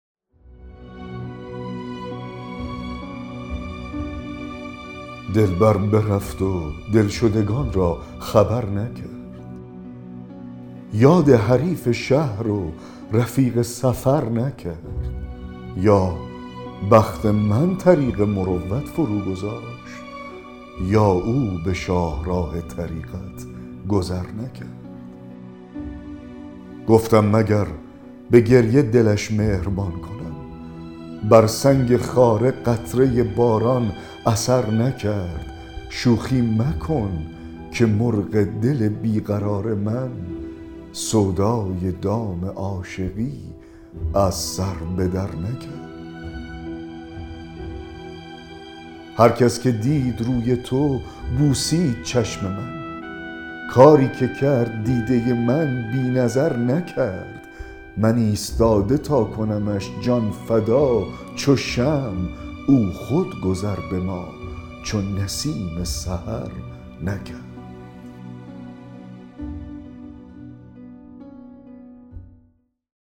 دکلمه غزل 140 حافظ